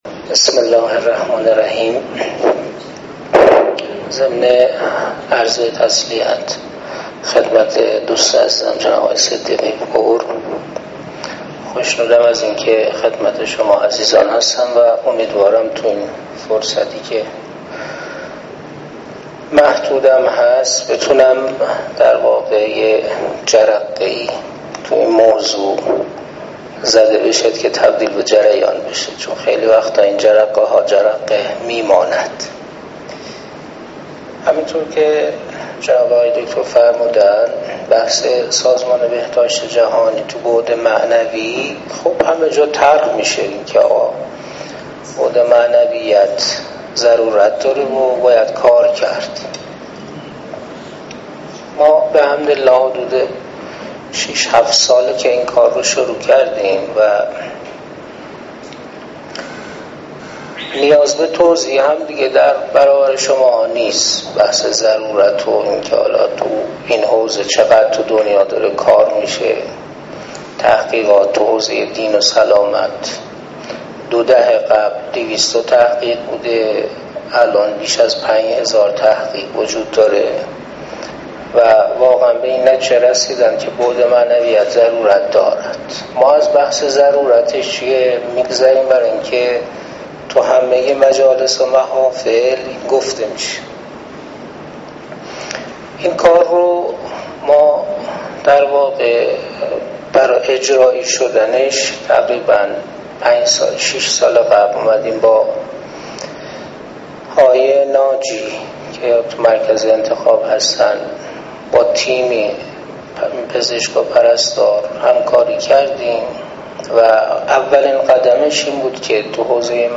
سخنرانی علمی کاربردی